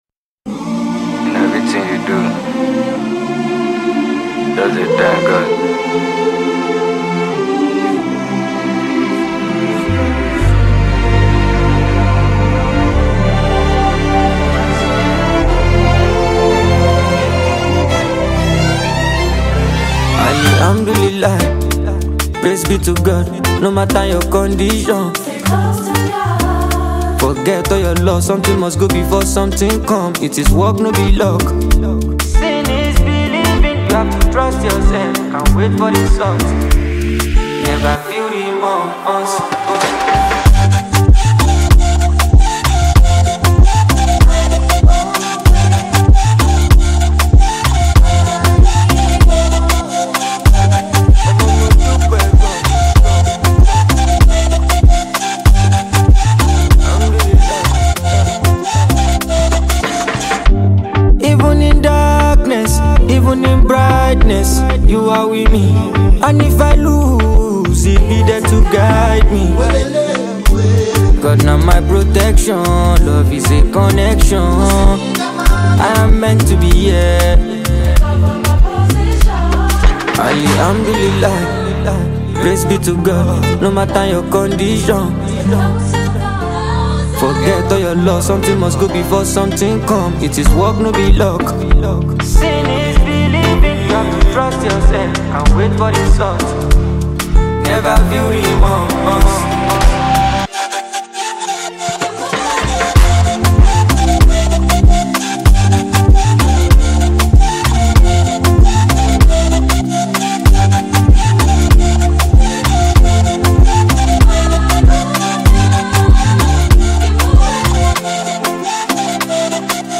a versatile singer songwriter admired for soulful delivery